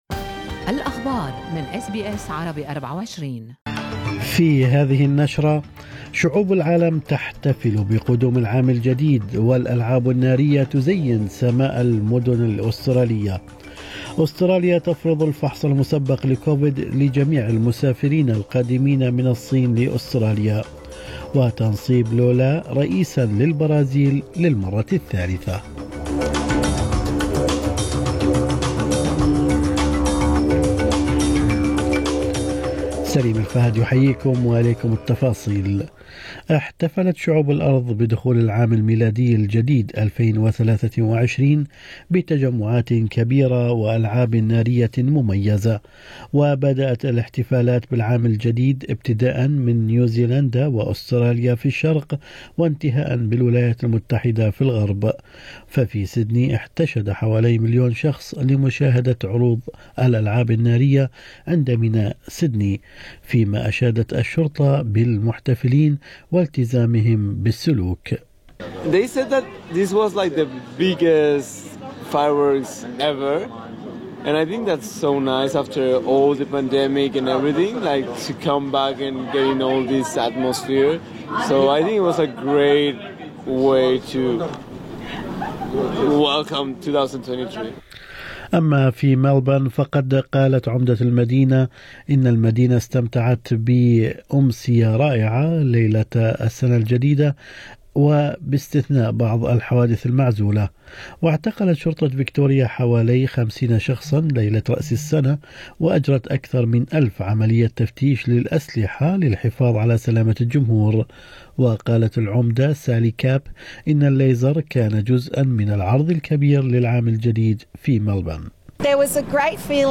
نشرة أخبار الصباح 2/1/2023